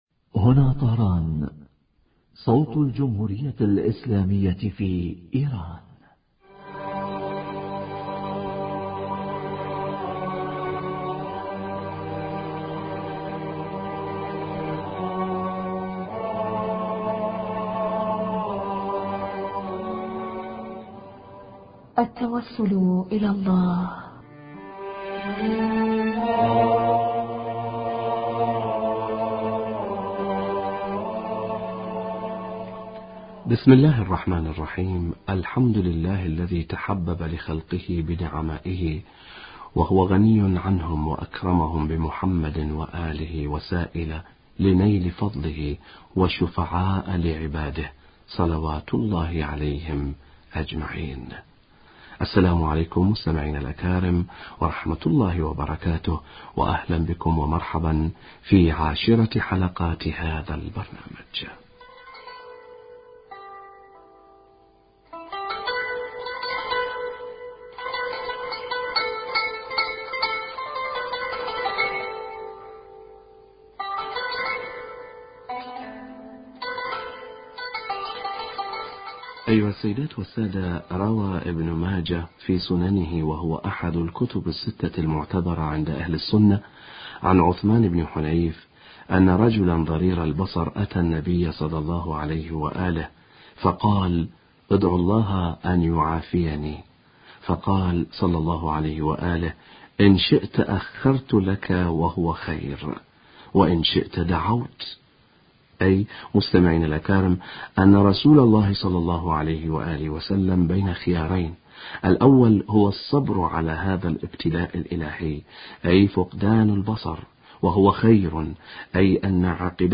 من روايات التوسل الى الله في كتب الجمهور حوار